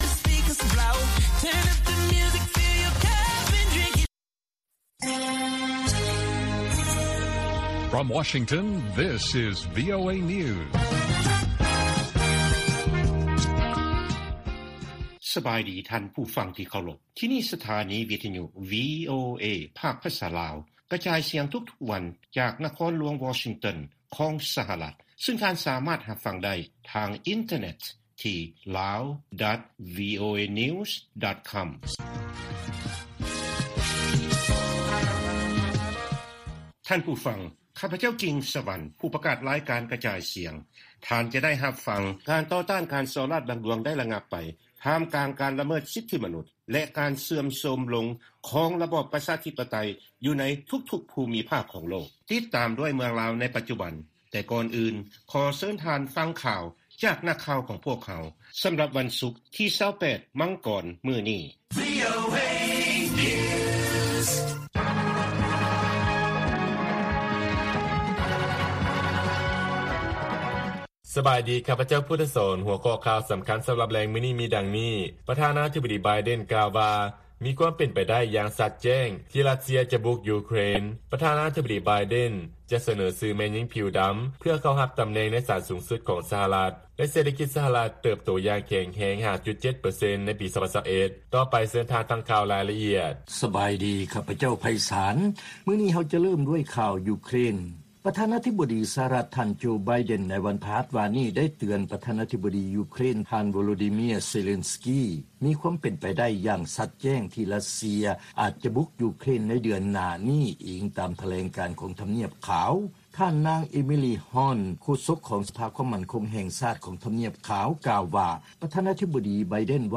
ລາຍການກະຈາຍສຽງຂອງວີໂອເອ ລາວ:ປະທານາທິບໍດີໄບເດັນ ກ່າວວ່າ ‘ມີຄວາມເປັນໄປໄດ້ ຢ່າງຊັດແຈ້ງ’ ທີ່ຣັດເຊຍ ຈະບຸກຢູເຄຣນ